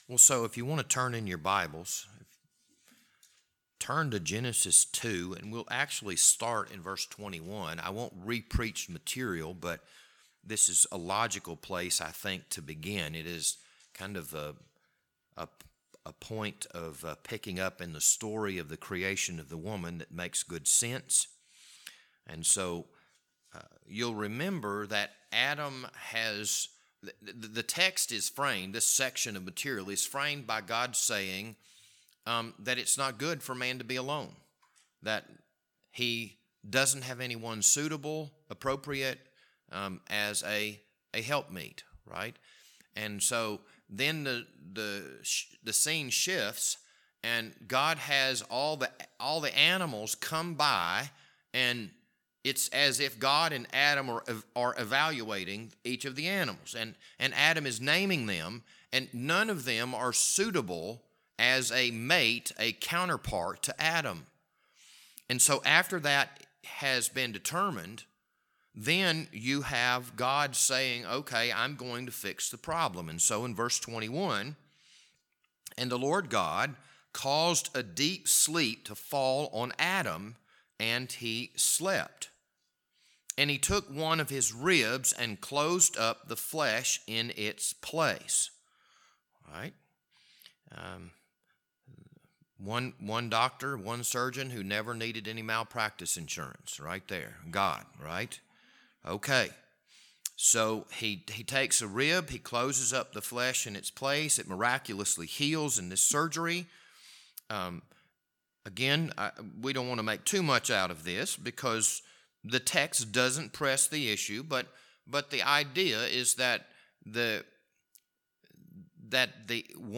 This Wednesday evening Bible study was recorded on April 6th, 2022.